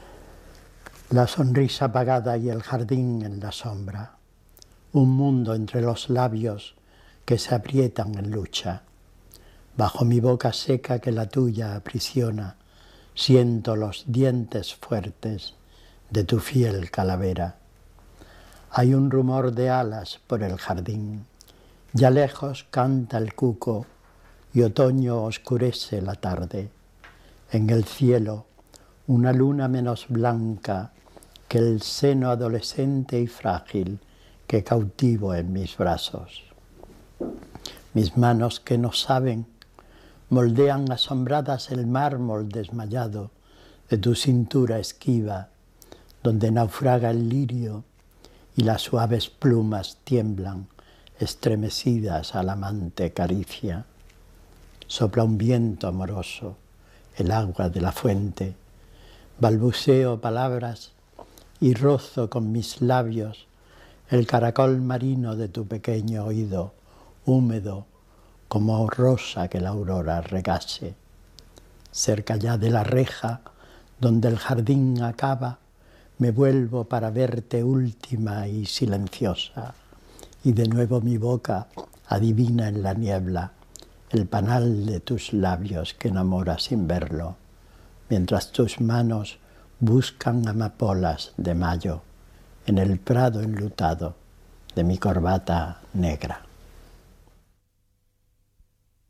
Autor del audio: El propio autor del poema